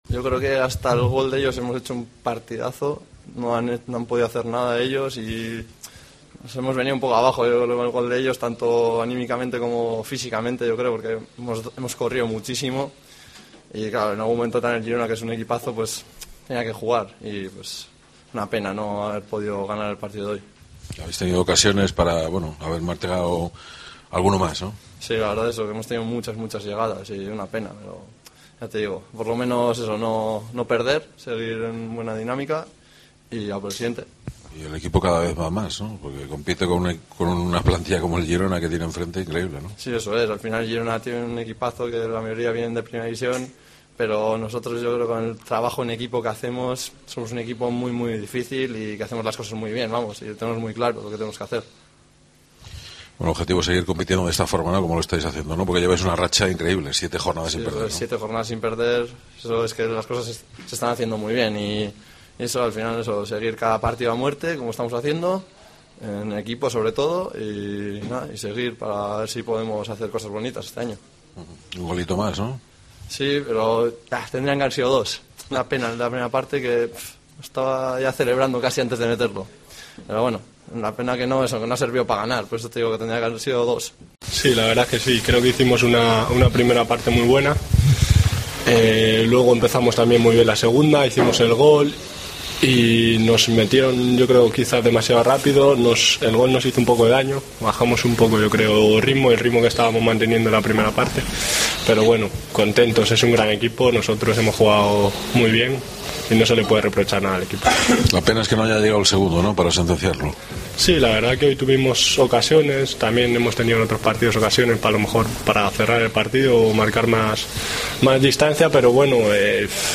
POSTPARTIDO
Escucha aquí las declaraciones de los dos jugadores blanquiazules